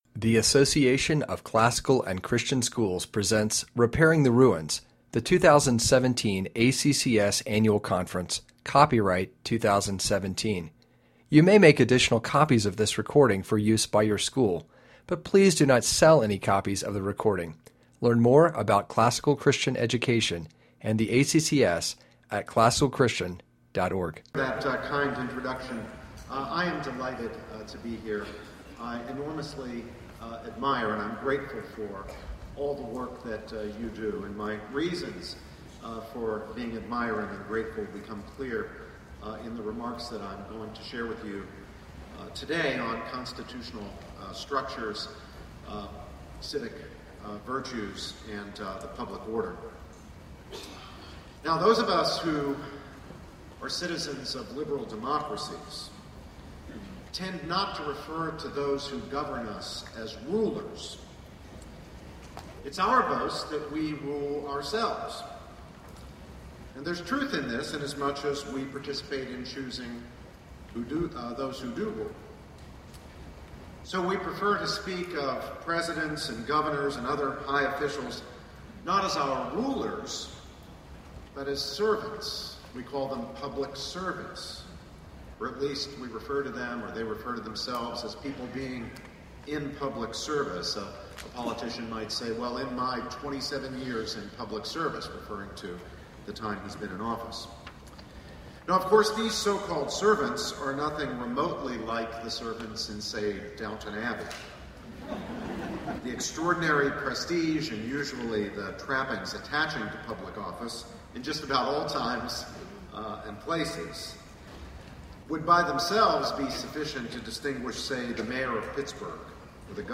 2017 Workshop Talk | 0:44:42 | All Grade Levels, General Classroom
Jan 9, 2019 | All Grade Levels, Conference Talks, General Classroom, Library, Media_Audio, Plenary Talk | 0 comments